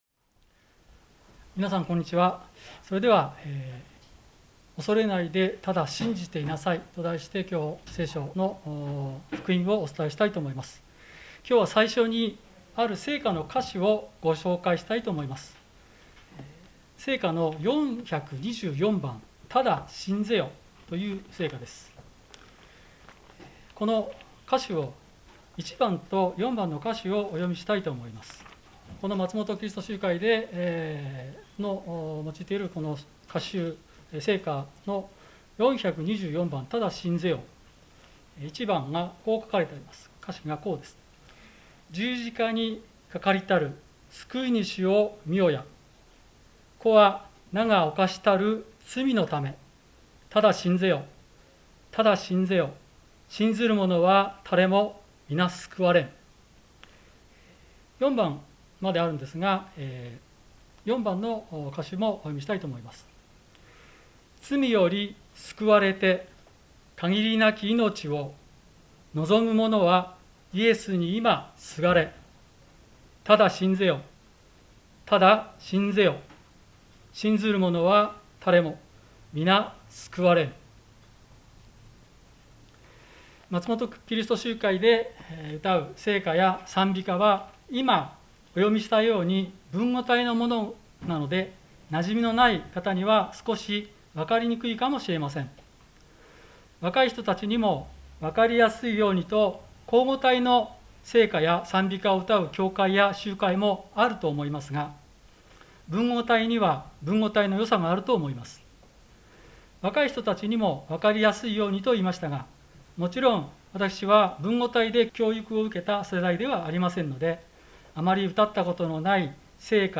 聖書メッセージ